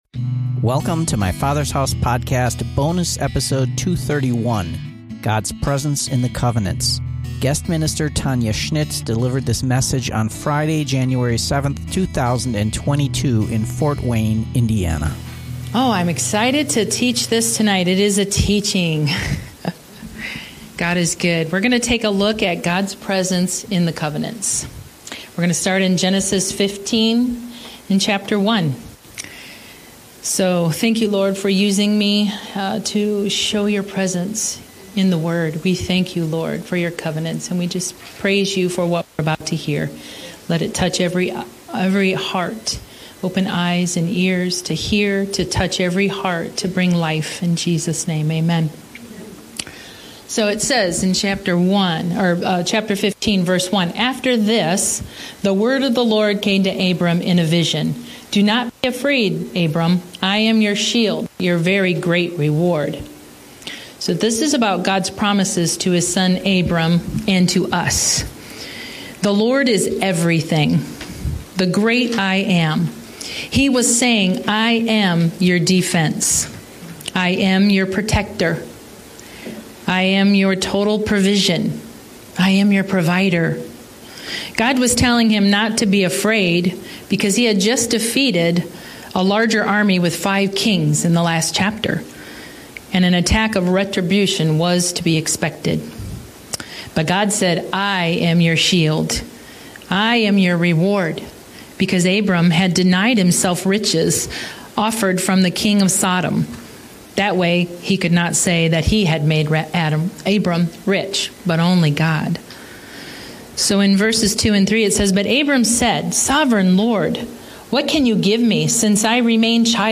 Teaching on fasting and prayer by Guest Minister